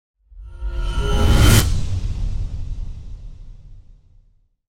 Fast Sharp Swoosh Sound Effect
Description: Fast sharp swoosh sound effect. Scary, dynamic sharp swoosh adds energy and motion to videos, games, and transitions.
Fast-sharp-swoosh-sound-effect.mp3